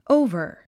発音
óuvər　オーバァ